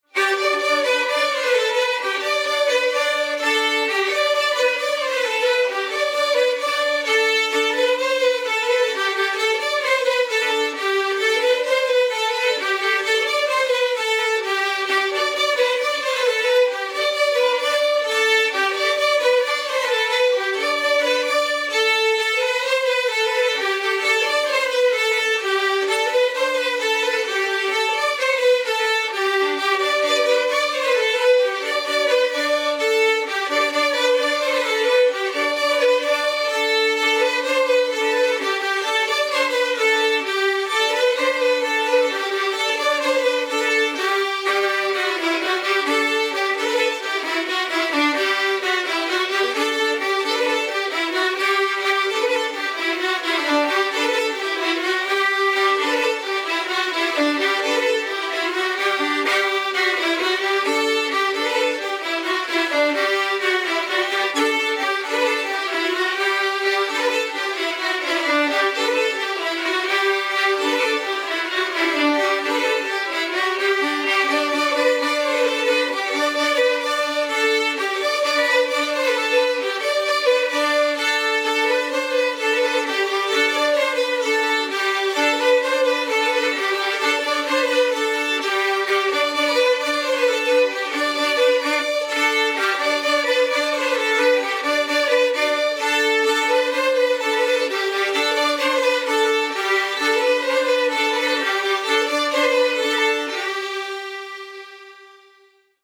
Les enregistrement de groupes d’élèves ont été effectués à la Carène, en avril 2016.
Le quadrille des ancêtres... au violon !